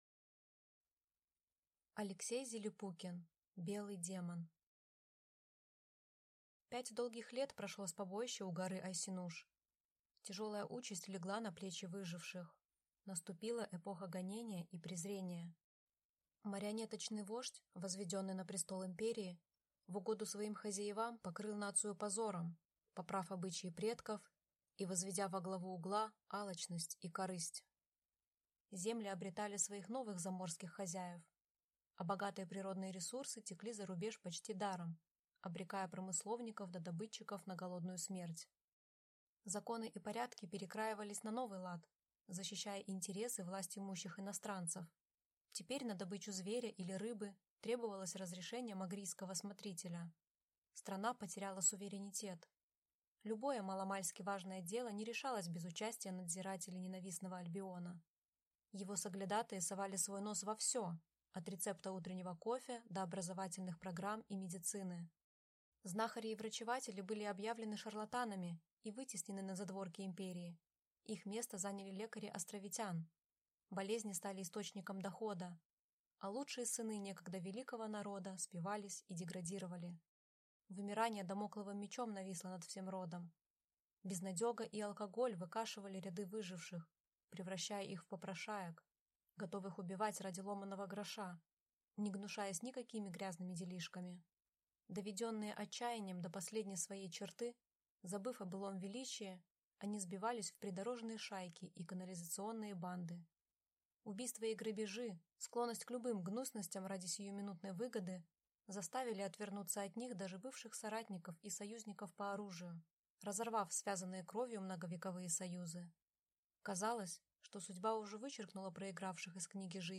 Аудиокнига Белый Демон | Библиотека аудиокниг
Прослушать и бесплатно скачать фрагмент аудиокниги